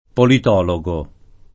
[ polit 0 lo g o ]